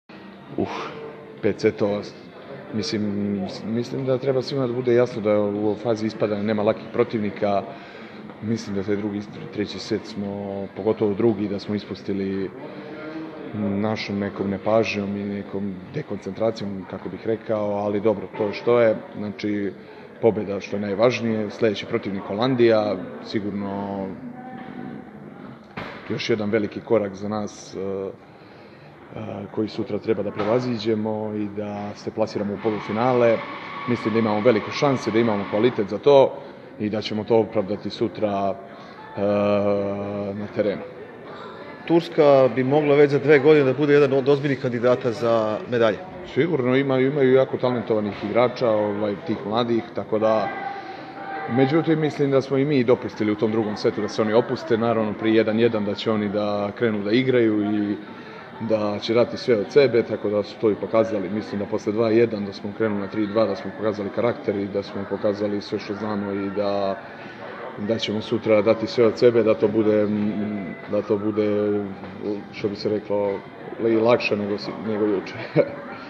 Izjava Petra Krsmanovića